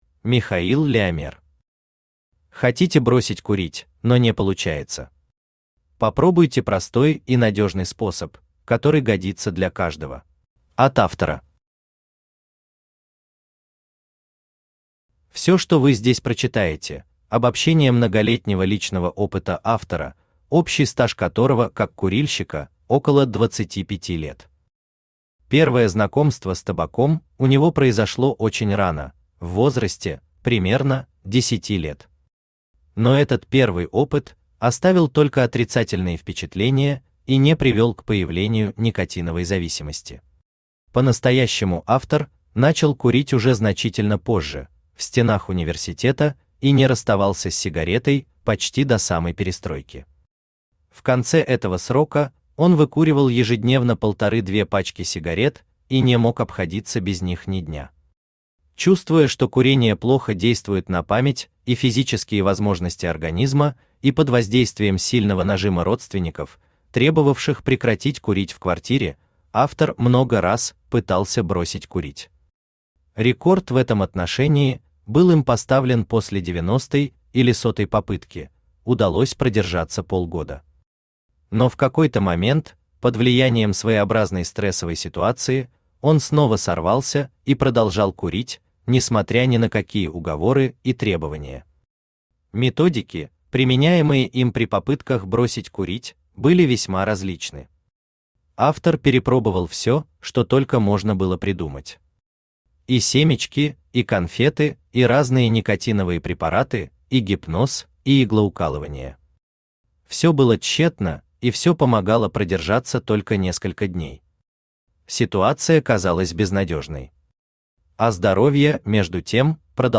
Автор Михаил Леомер Читает аудиокнигу Авточтец ЛитРес.